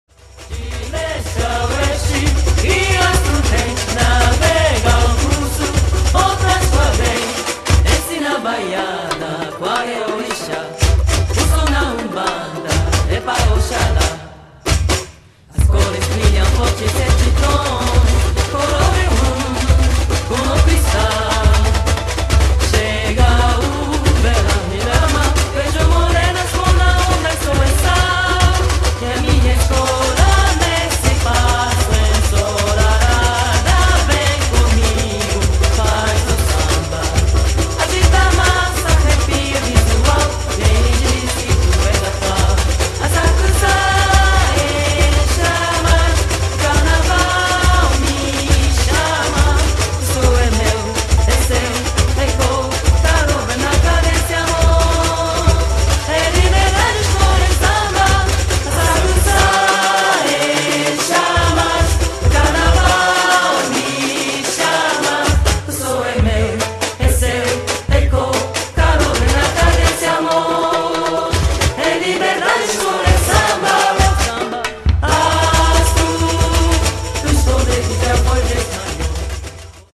2006年浅草のSambas de Enredo